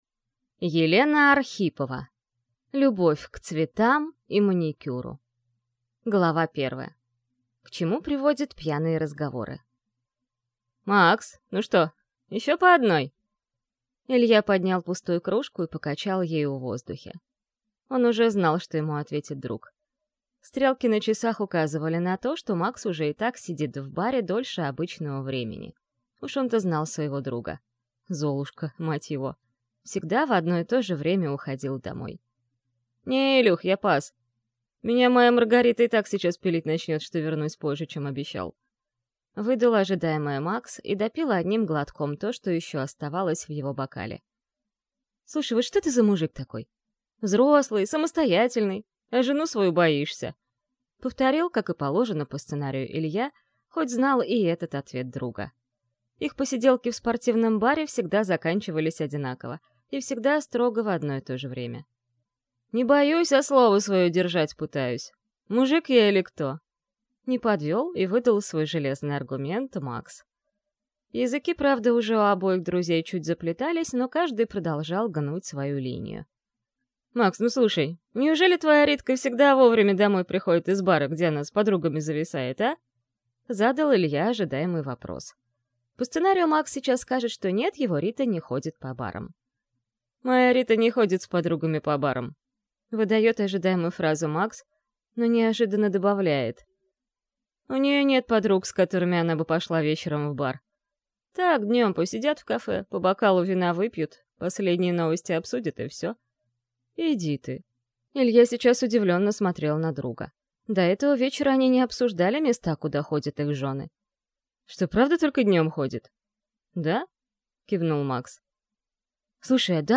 Аудиокнига Любовь к цветам и маникюру | Библиотека аудиокниг